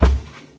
minecraft / sounds / mob / cow / step3.ogg
step3.ogg